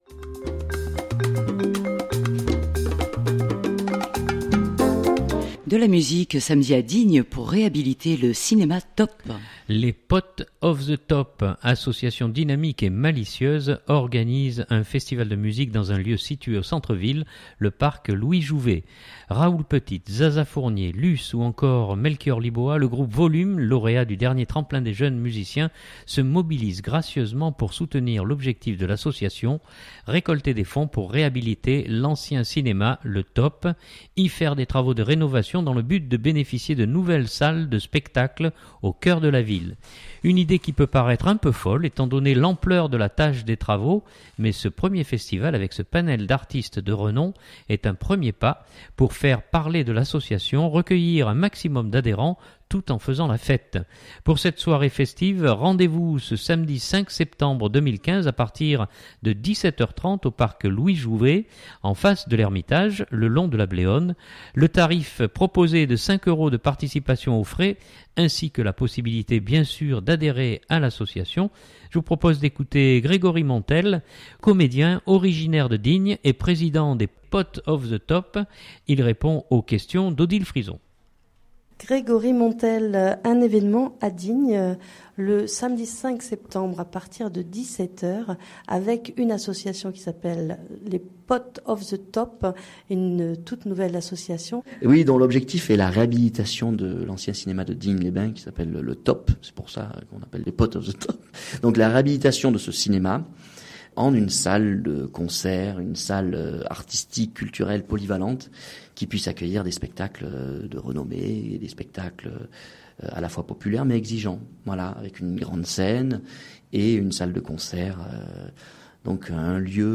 Je vous propose d’écouter Grégory Montel, comédien originaire de Digne et Président des Potes of the Top.